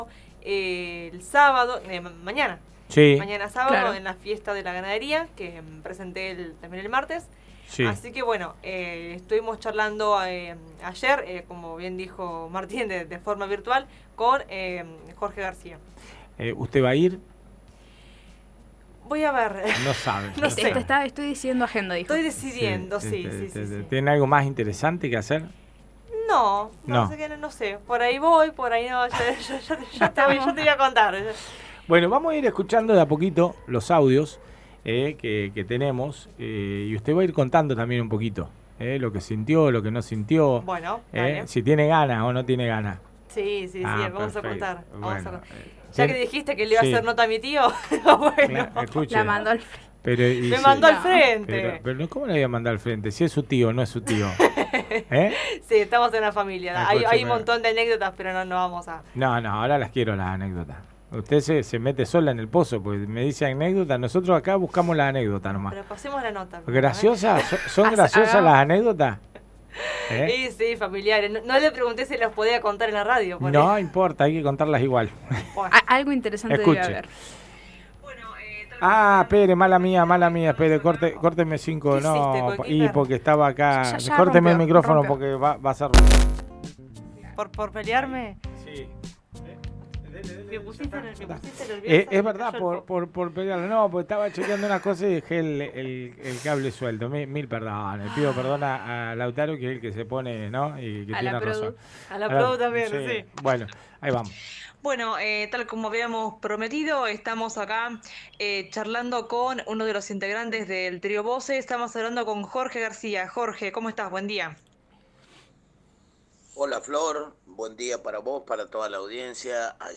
AGENDA CULTURAL